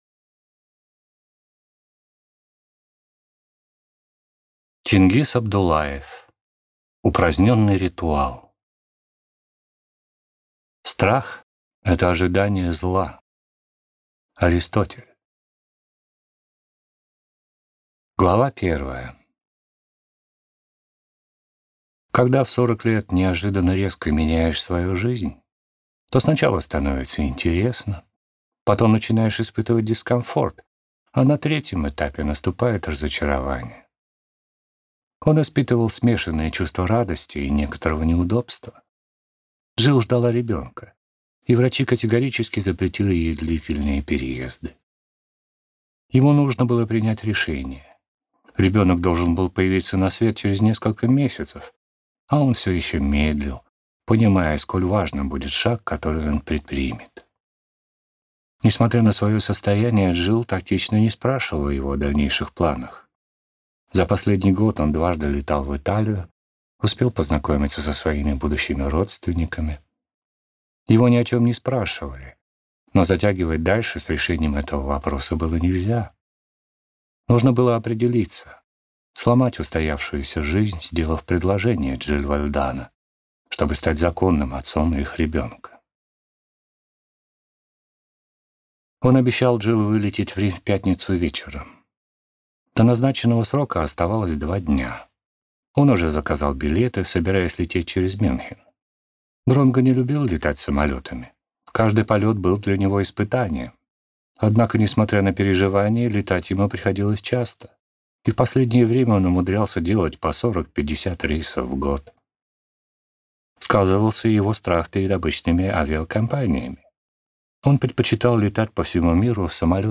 Аудиокнига Упраздненный ритуал | Библиотека аудиокниг
Прослушать и бесплатно скачать фрагмент аудиокниги